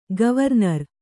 ♪ gavarnar